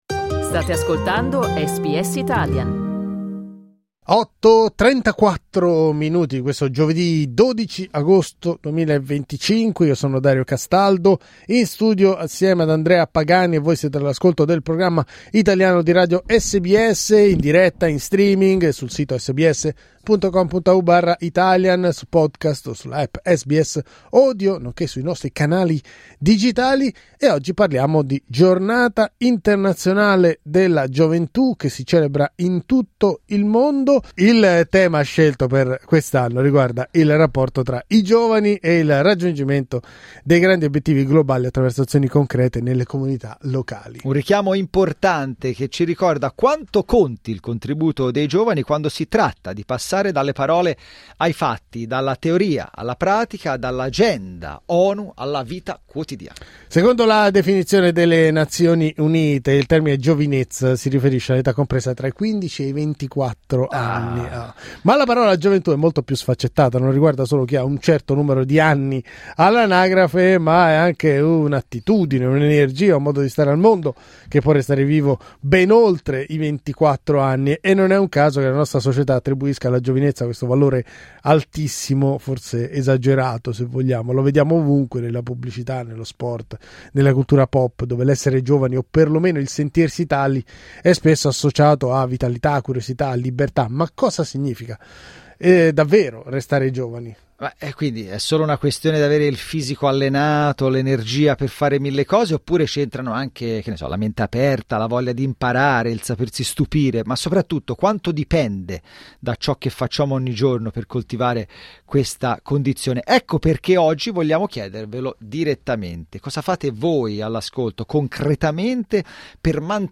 Più di 100mila persone parteciperanno domenica al tradizionale evento sulla Great North Road. Ne abbiamo parlato con organizzatori e artisti coinvolti.